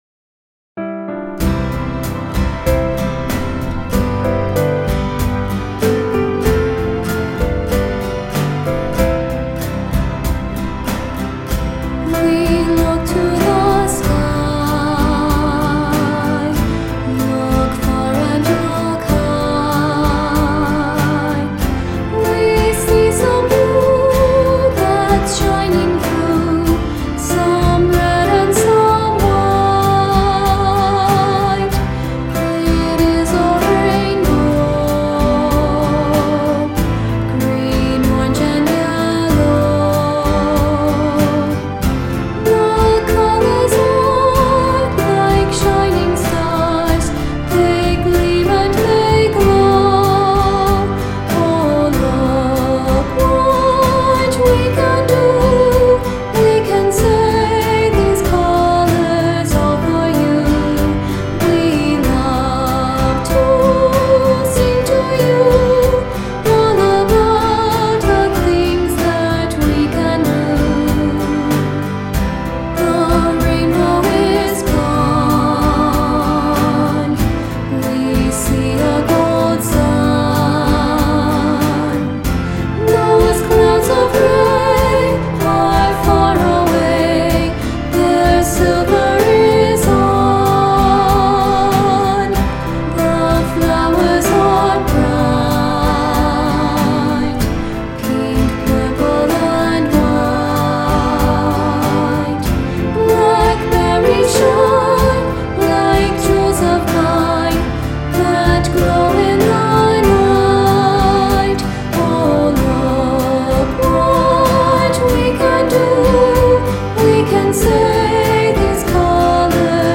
Children’s Songs